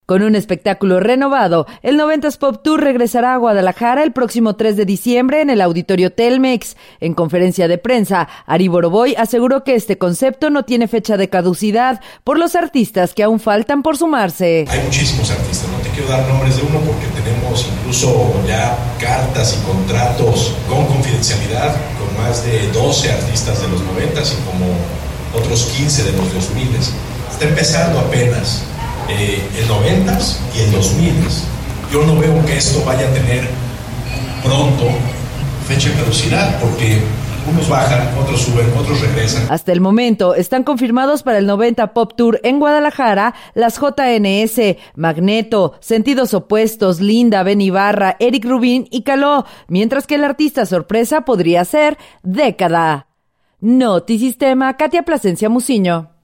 Con un espectáculo renovado, el 90’s Pop Tour regresará a Guadalajara el próximo 3 de diciembre en el Auditorio Telmex. En conferencia de prensa, Ari Borovoy aseguró que este concepto no tiene fecha de caducidad, por los artistas que aún faltan por sumarse.